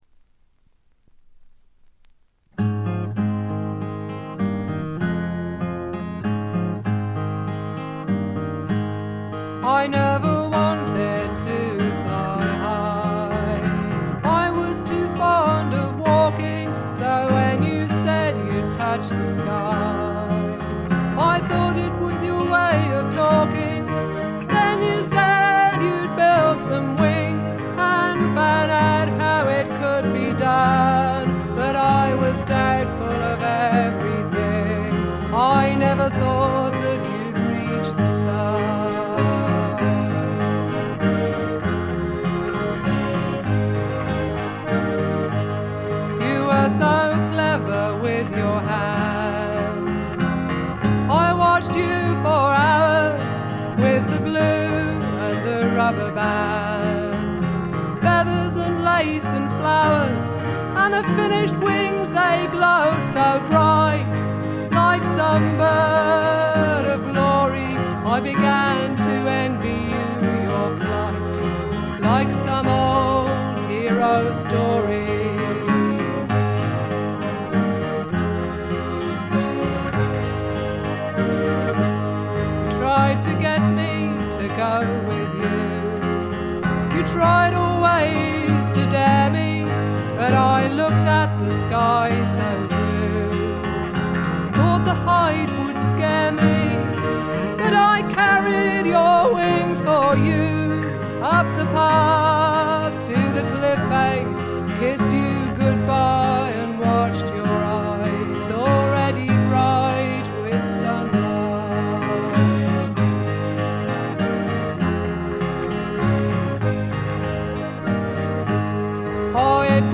英国の女性フォークシンガー／ギタリスト。
味のあるアルとト・ヴォイスで淡々と歌っていクールなものです。
試聴曲は現品からの取り込み音源です。
Vocals, Guitar, Electric Bass, Banjo